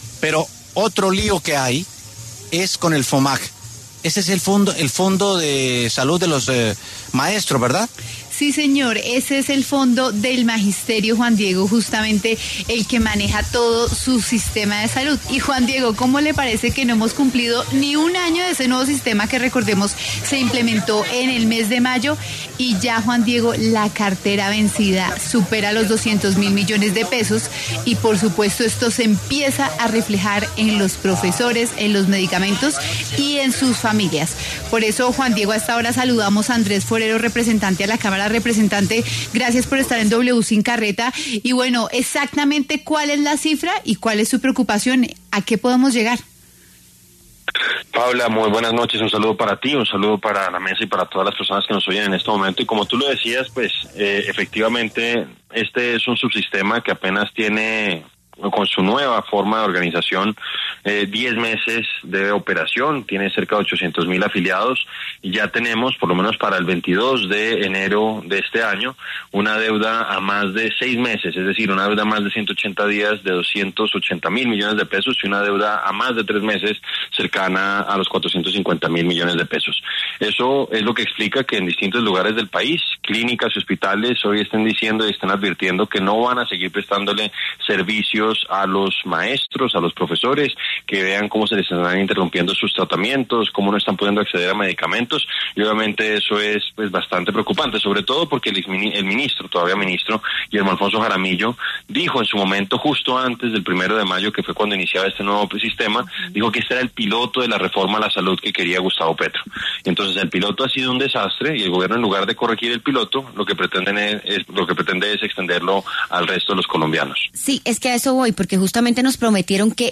Andrés Forero, representante a la Cámara, estuvo en W Sin Carreta en donde abordó la problemática situación que enfrenta el Fondo de Salud del Magisterio, conocido como FOMAG.